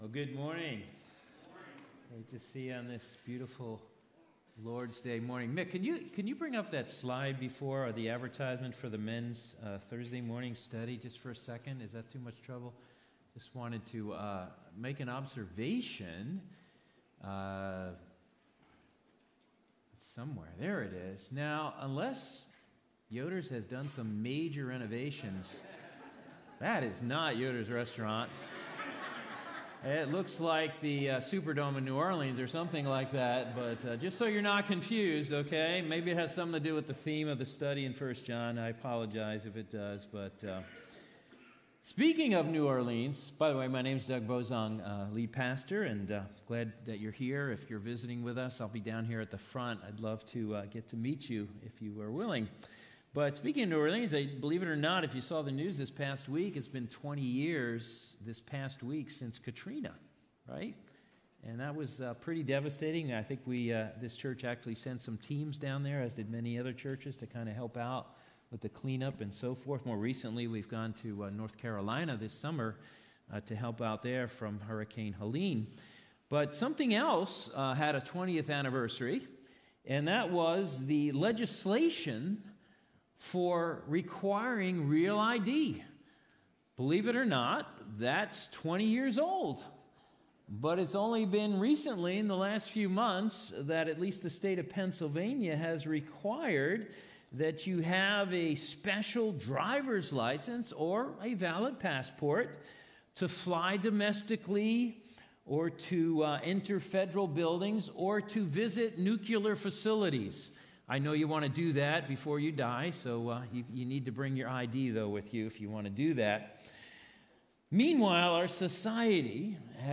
Sermons | Christian Fellowship Church